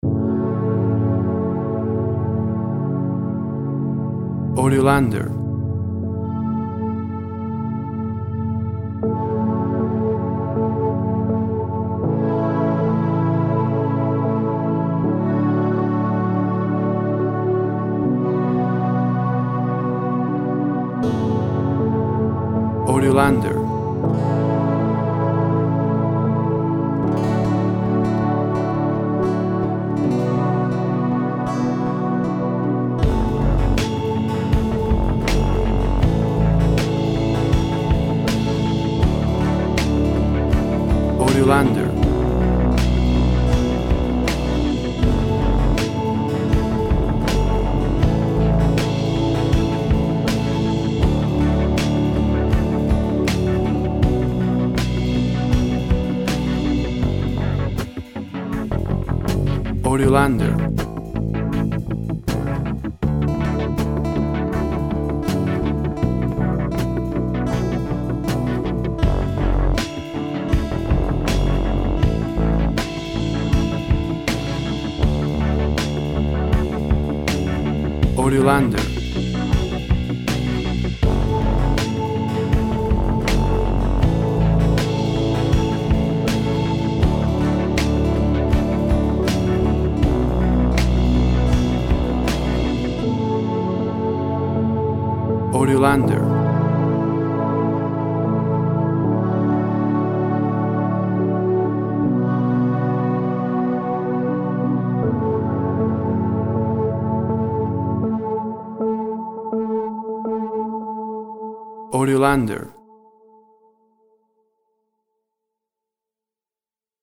Inspiration music.
Tempo (BPM) 80